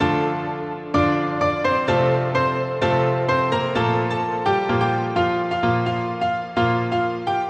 标签： 128 bpm Electronic Loops Piano Loops 1.26 MB wav Key : Unknown
声道立体声